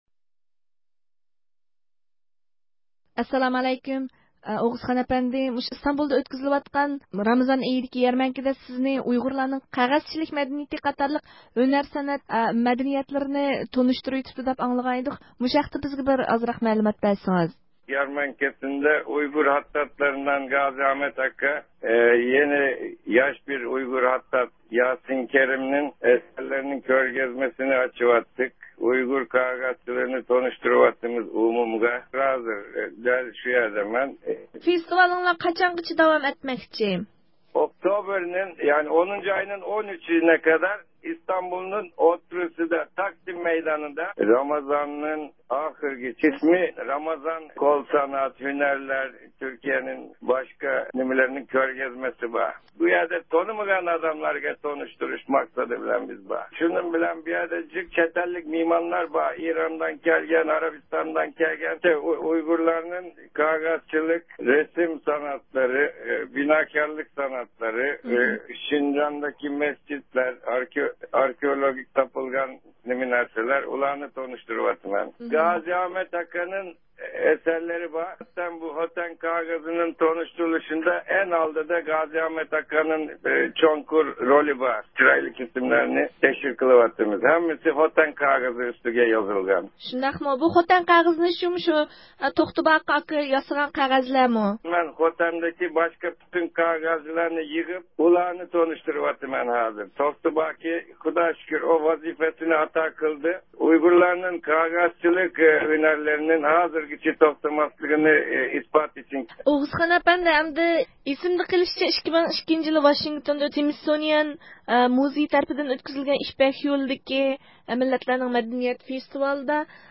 تېلېفون زىيارىتىنى قوبۇل قىلىدۇ.